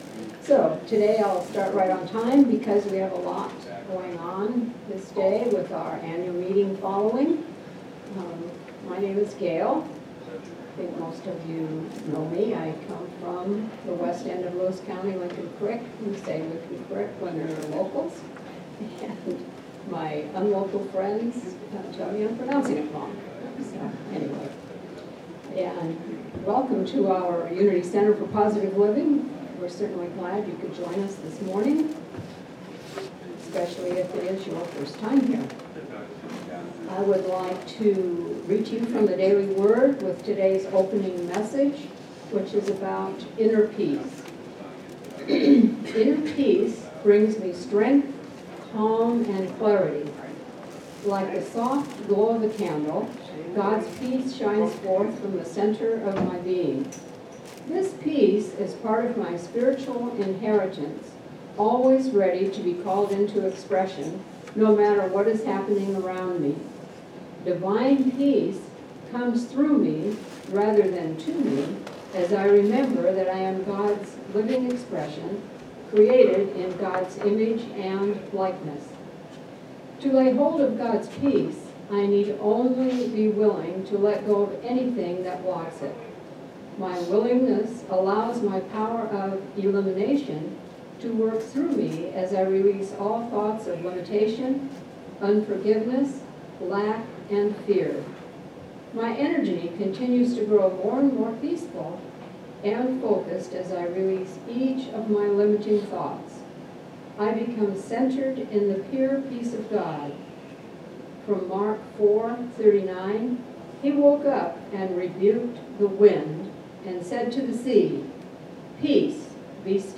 January 12, 2020 Service